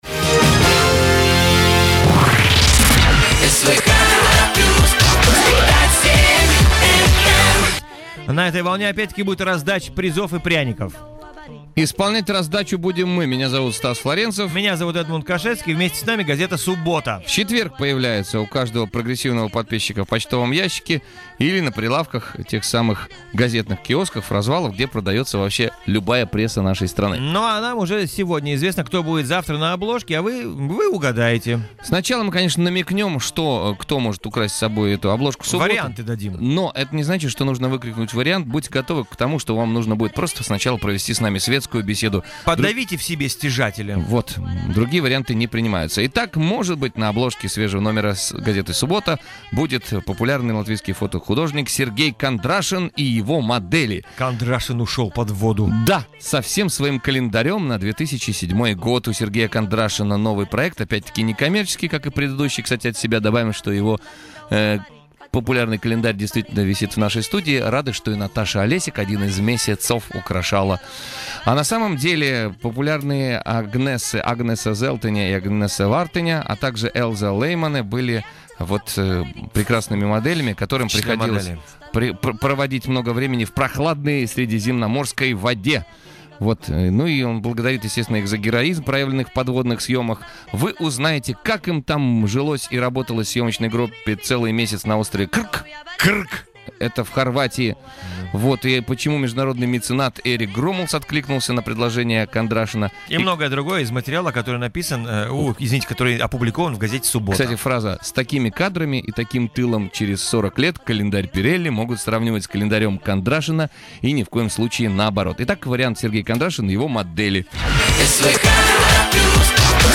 SWH +, радио эфир 2007 года о календаре